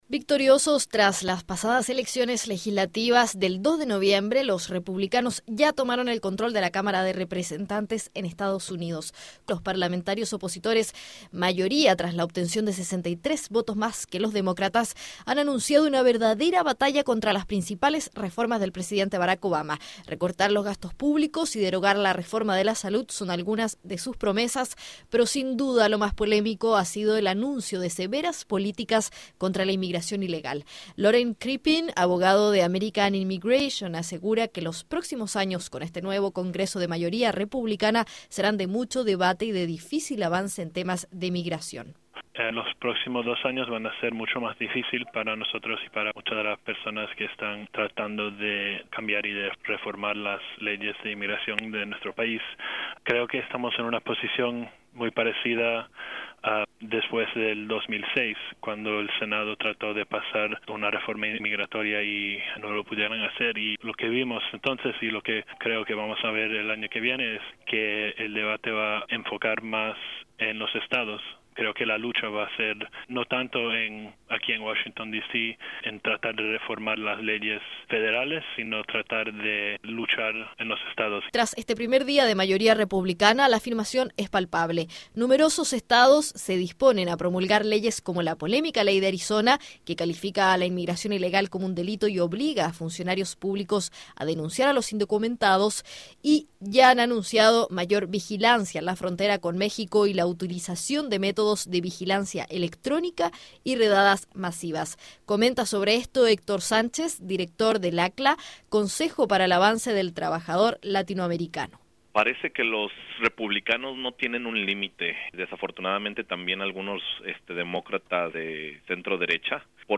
El Congreso de EEUU, donde se discuten algunas de las reformas del presidente Barack Obama, está dividido. Escuche el informe de Radio Francia Internacional.